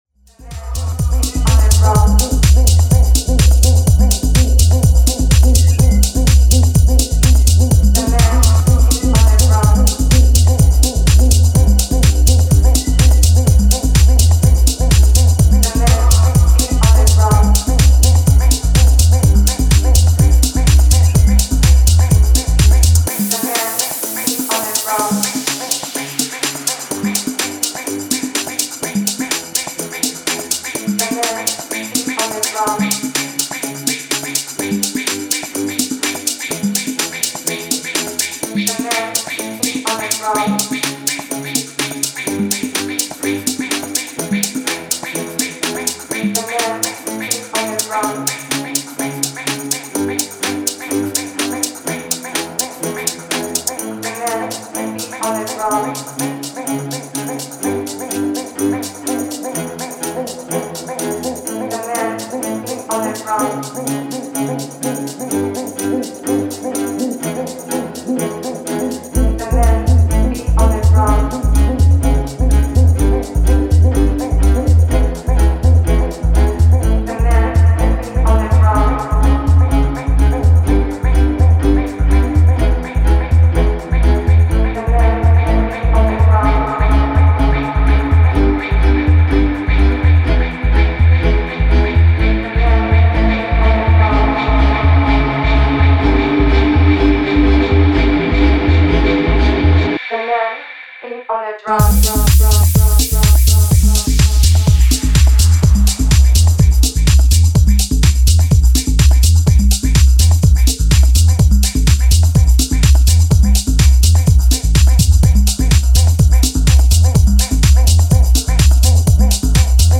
the driving percussive techhouse of south american open airs
Style: House